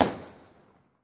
烟花爆炸
爆炸大小：中等
标签： 录音 热潮 卡西欧 爆炸 爆炸 烟火 爆炸 样品
声道立体声